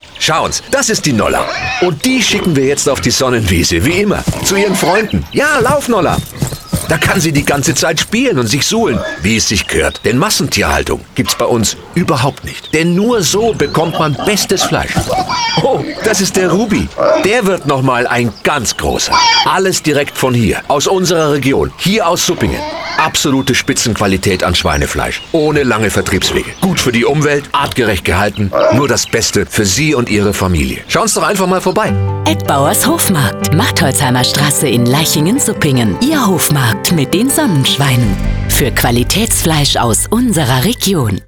Commercial Hofmarkt